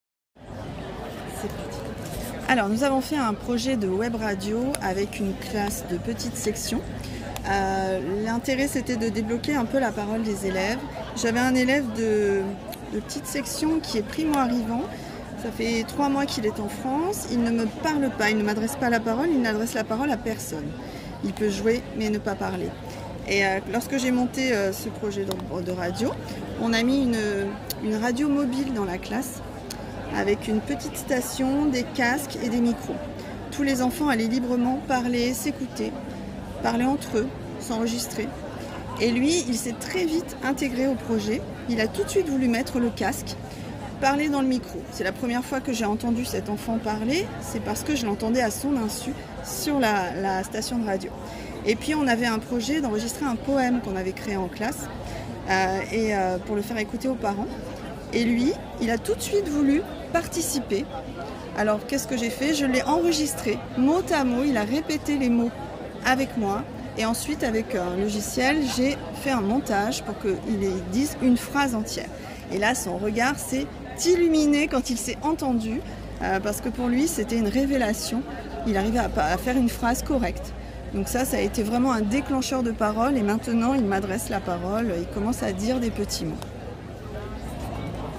En direct de la Journée académique pédagogie et innovation « Japi‘ » portée par la CARDIE et l’EAFC, nous avons recueilli des témoignages d’enseignantes sur l’appropriation par les élèves allophones des projets proposés en classe.